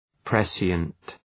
Προφορά
{‘presıənt}